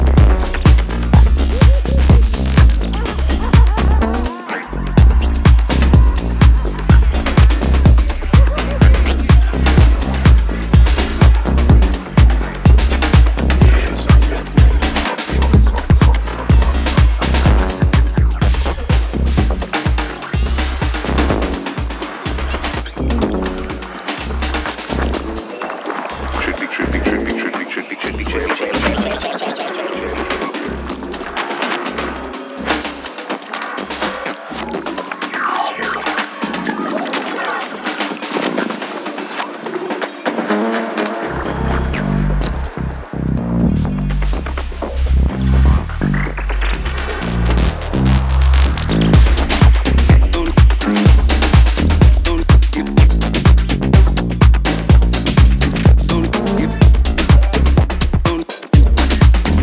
Packed with mesmerising minimal sounds